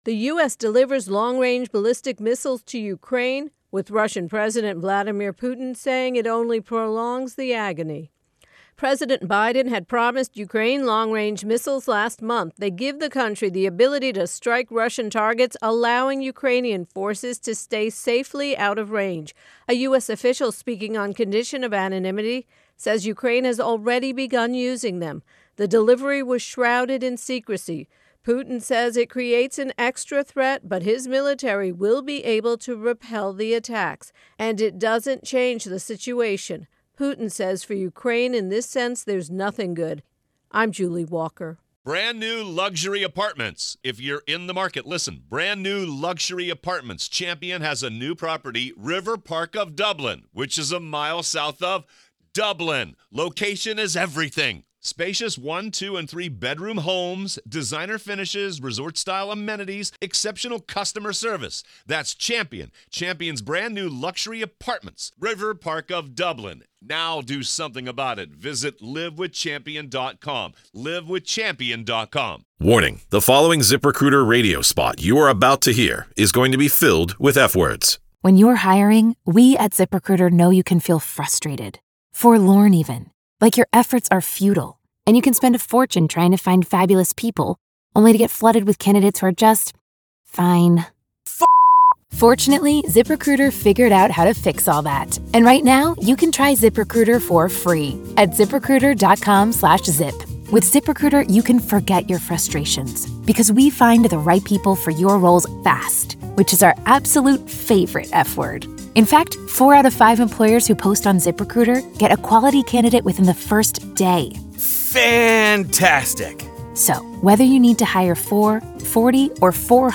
((Updated version of earlier intro + voicer))